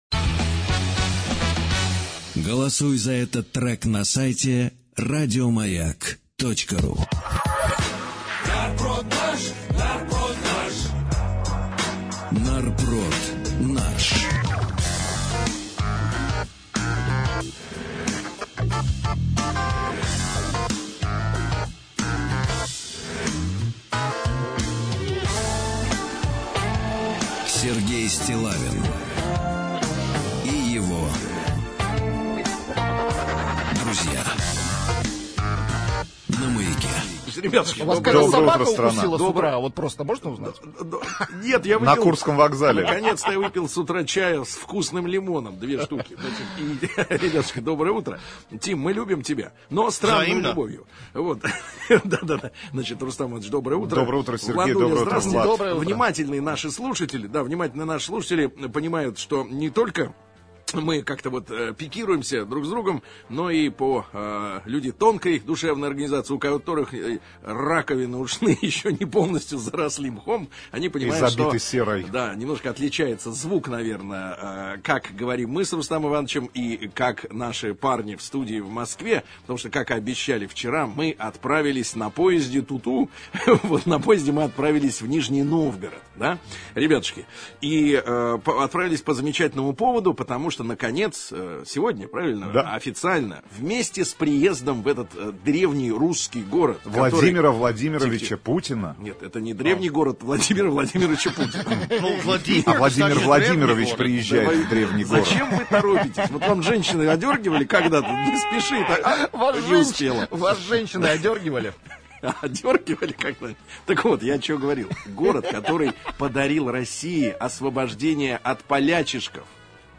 Утреннее шоу на Маяке провело эфир 29 марта 2016 года из Нижнего Новгорода.